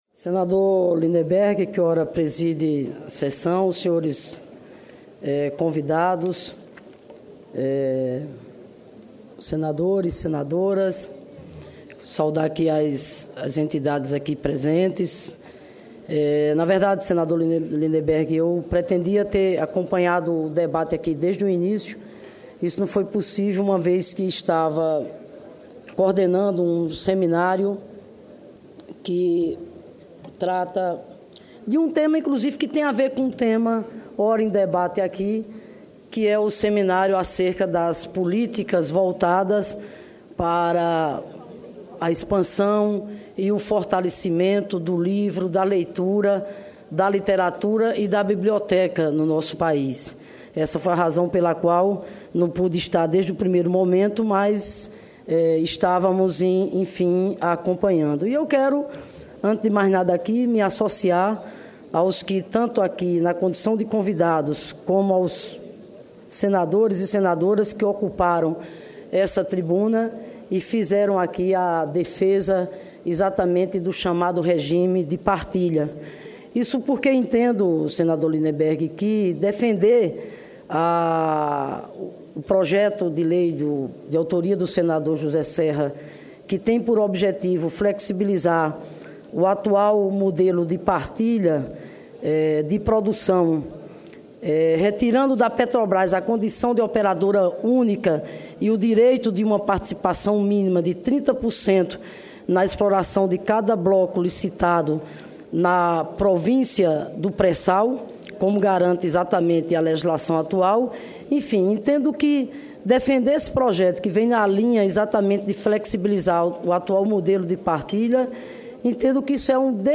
Pronunciamento da senadora Fátima Bezerra